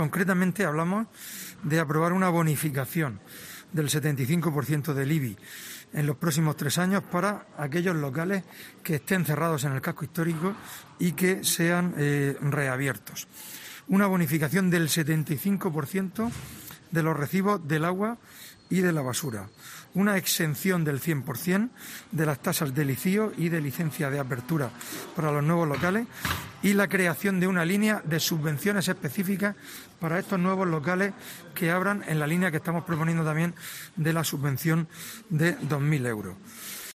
Fulgencio Gil, portavoz del PP